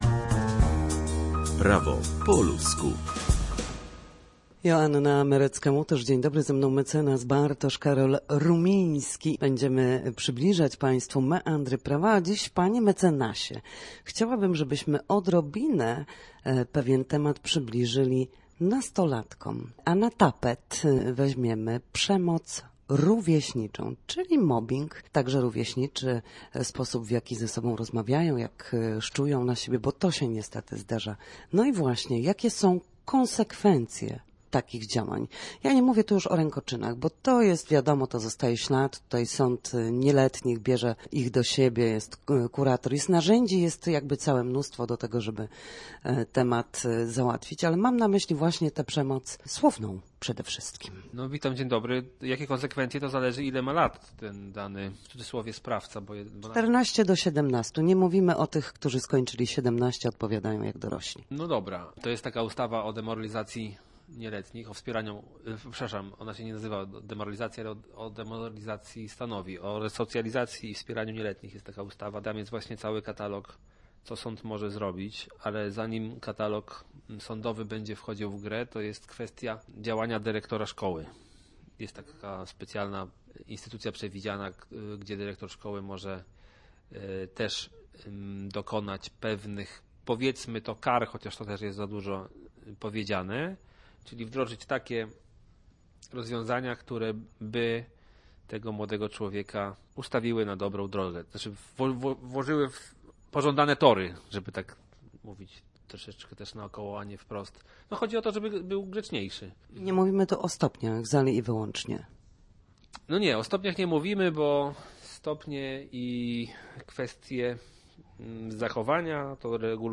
W każdy wtorek o godzinie 13:40 na antenie Studia Słupsk przybliżamy Państwu meandry prawa. W naszym cyklu prawnym gościmy ekspertów, którzy odpowiadają na jedno konkretne pytanie związane z zachowaniem w sądzie lub podstawowymi zagadnieniami prawnymi.